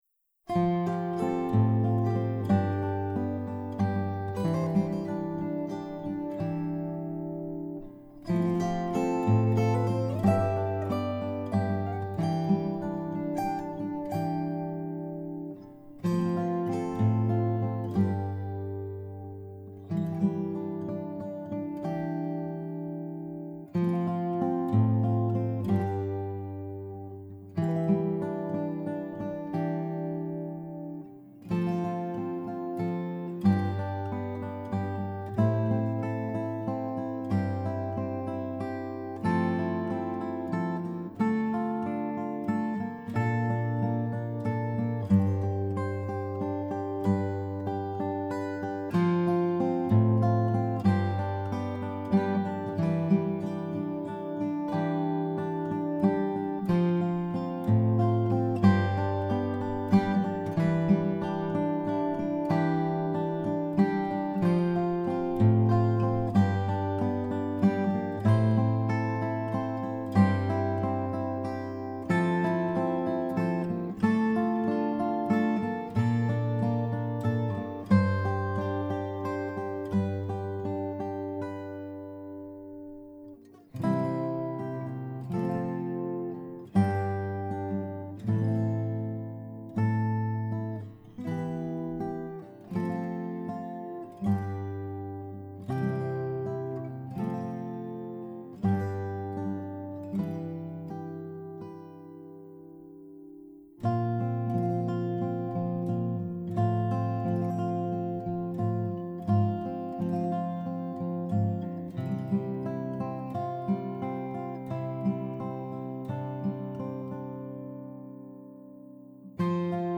Below are new guitar recordings for my songs “Someone to Love You” and “How We Don’t Care.”